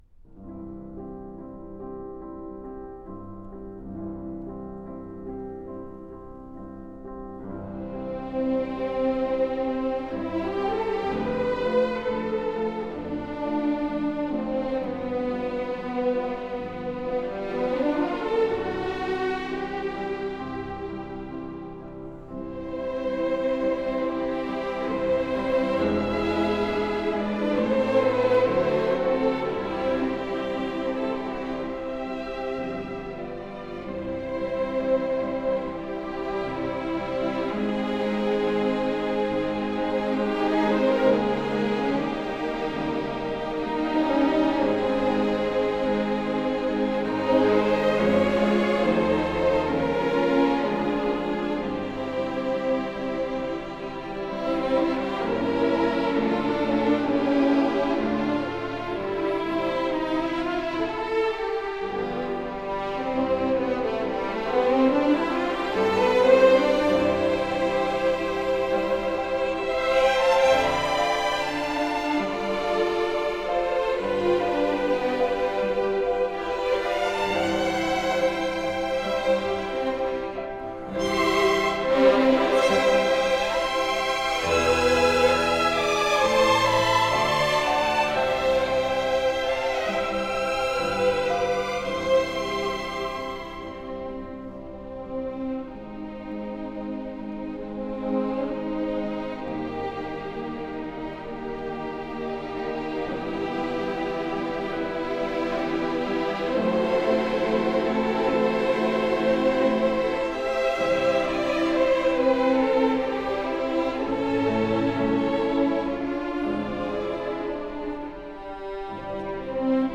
1995年2月12曰至15曰在位于基辅的乌克兰广播电台音乐厅录音完成